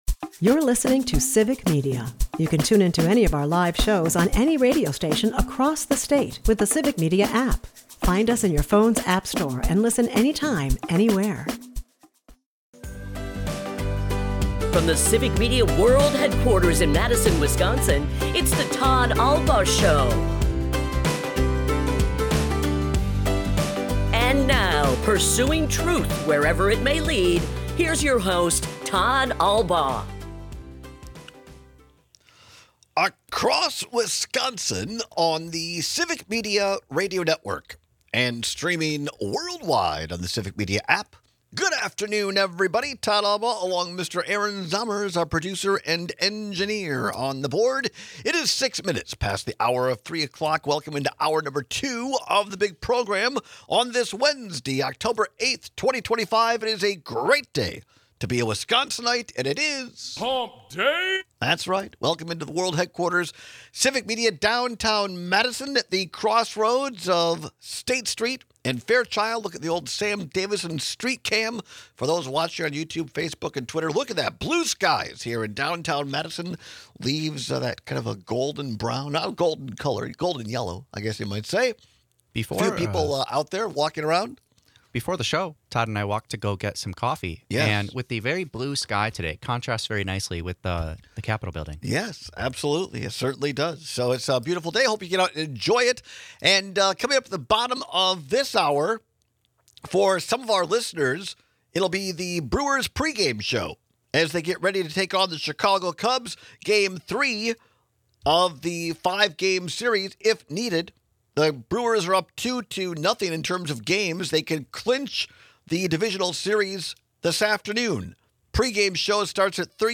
We kick off our second hour with our own Brewers pregame show.
We take a few calls and texts with lighthearted takes.